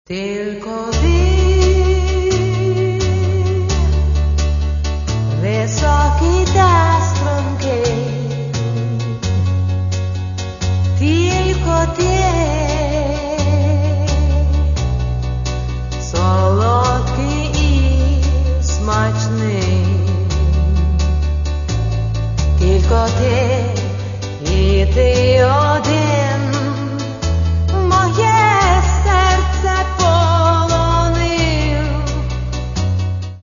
Каталог -> Поп (Легкая) -> Юмор
Легкая и энергичная музыка, шутка в каждой строке.